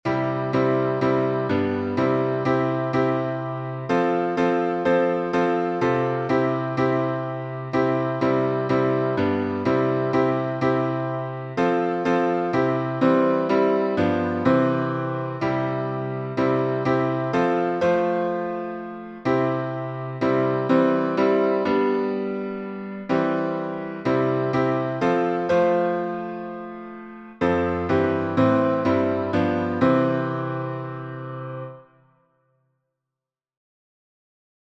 #5109: Jesus Loves Me — C major, two four | Mobile Hymns